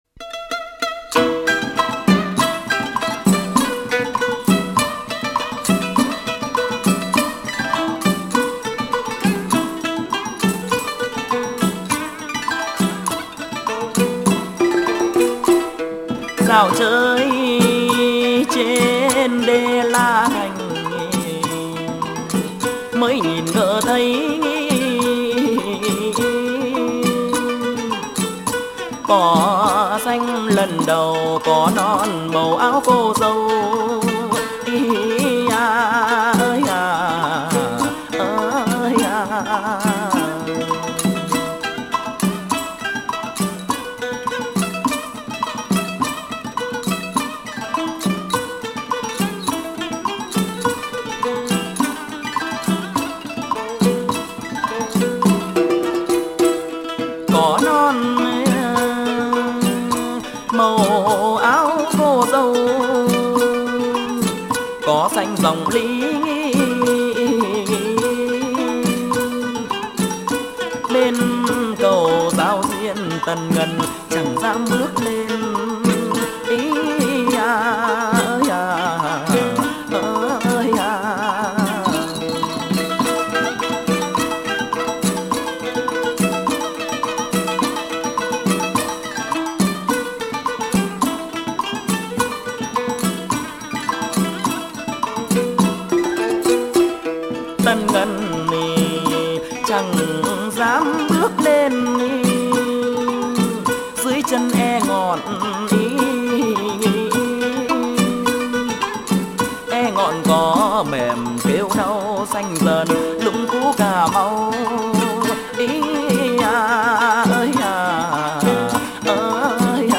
Thể loại: Hát văn